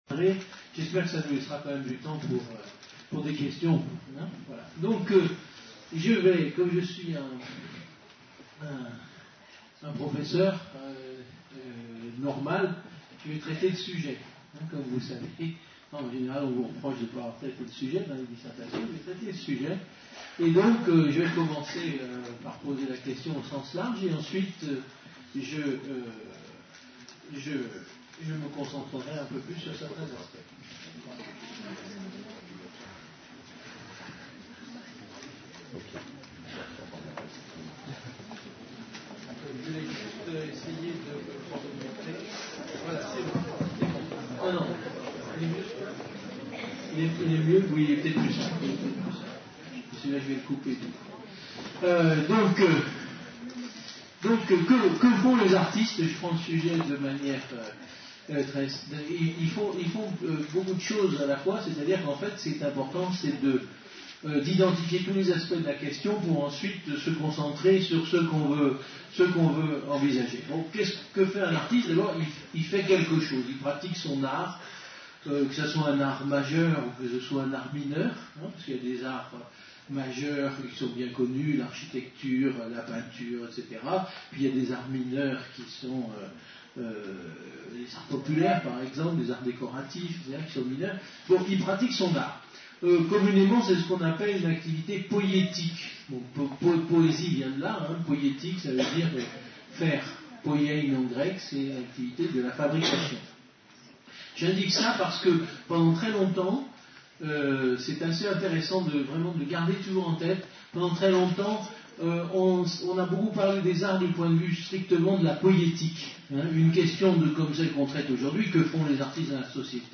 Lycée Pierre et Marie Curie, Menton (06)